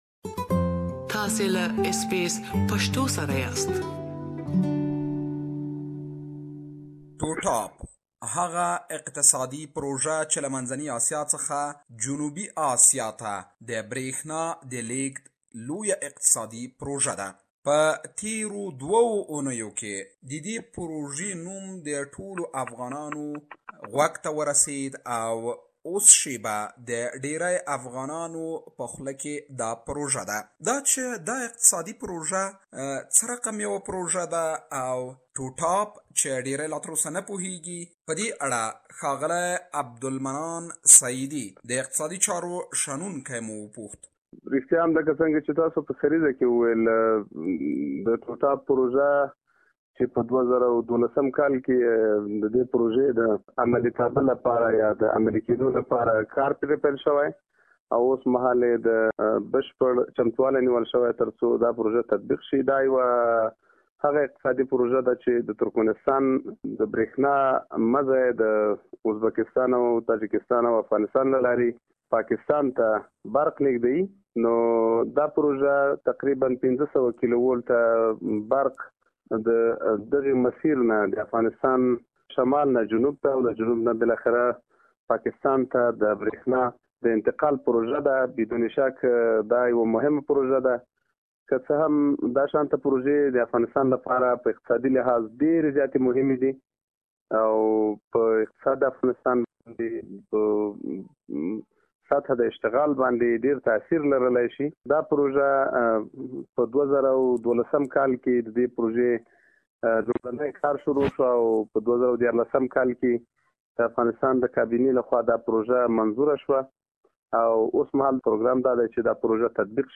TUTAP project is one of significant and historic value for Afghanistan. This project has many economic benefits where Afghanistan can not only benefit from its electricity but moreover the country could benefit millions of dollars each year. We have interviewed political and economic analyst